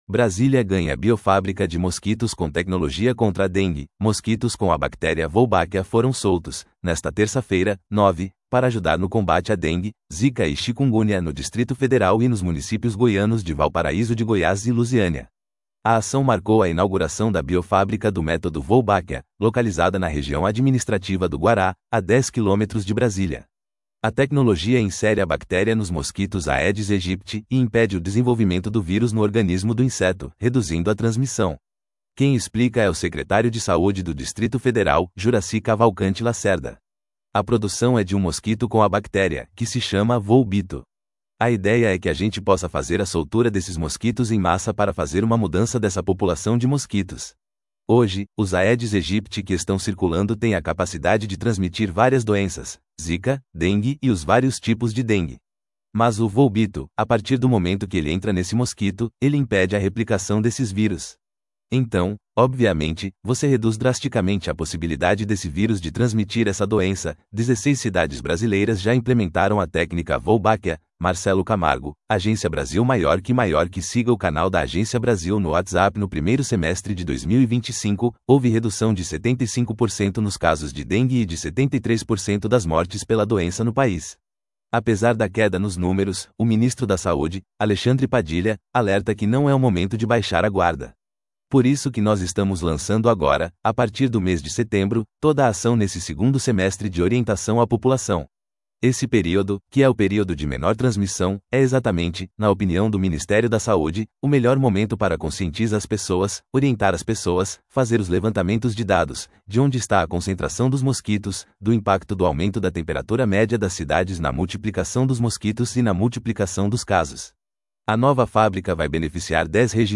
Quem explica é o secretário de Saúde do Distrito Federal, Juracy Cavalcante Lacerda.
Apesar da queda nos números, o ministro da Saúde, Alexandre Padilha, alerta que não é o momento de baixar a guarda.